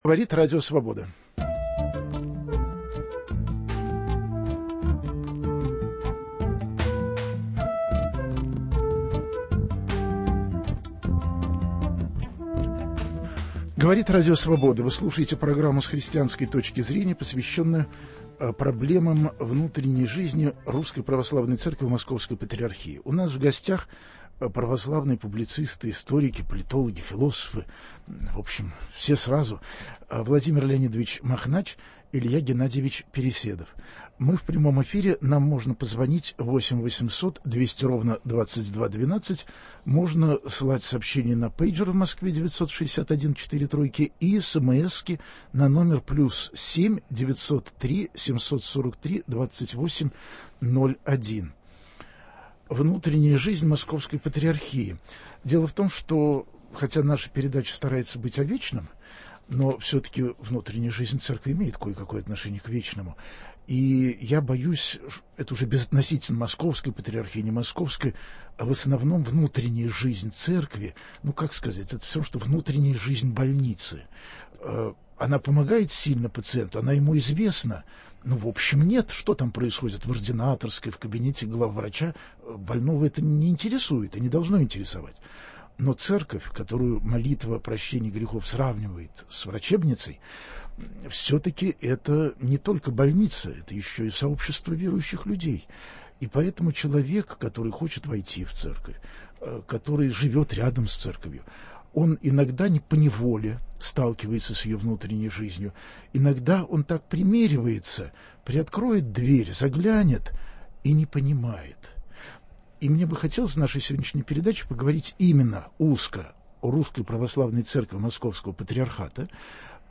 Разговор о событиях, которые сегодня волнуют Московскую Патриархию: отношение к государственной власти, к созыву поместного собора, к национальному вопросу.